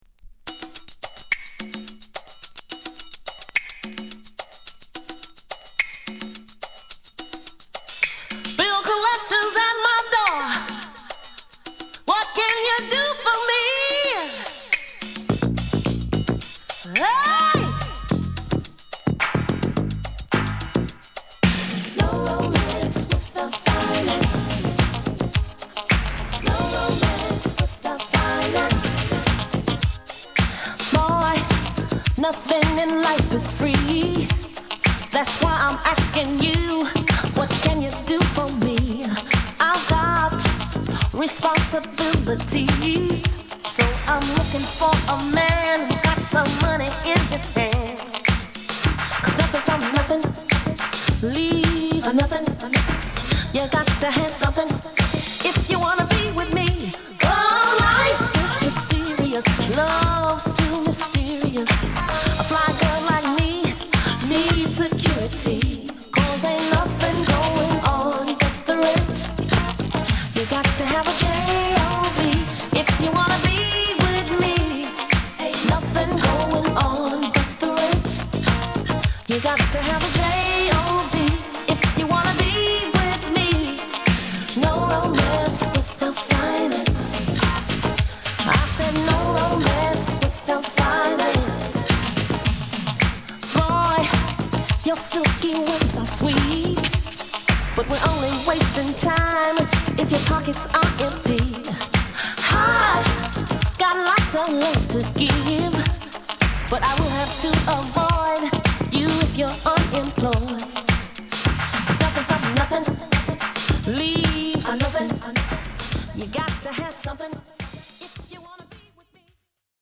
蠢くベース・ラインと綺羅びやかなシンセ使いは今効いても抜群のクオリティ！...